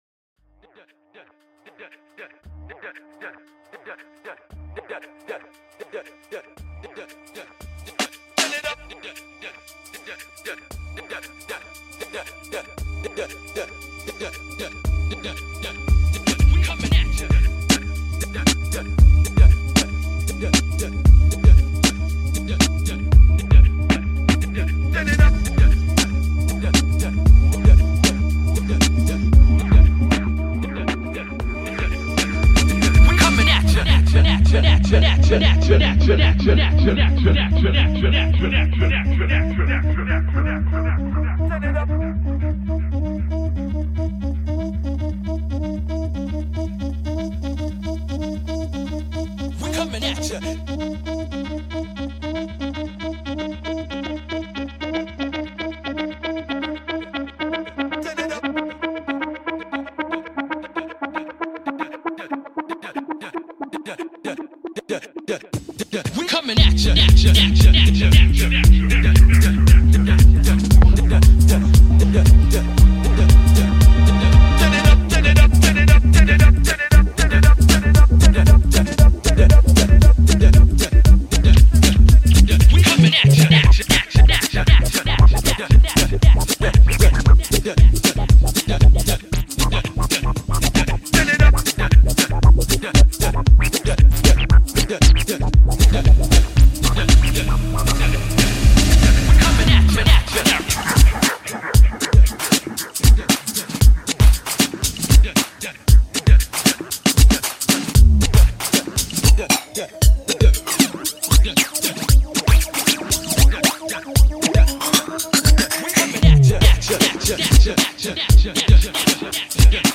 Électro Éclectique
bpm 116-133 bpm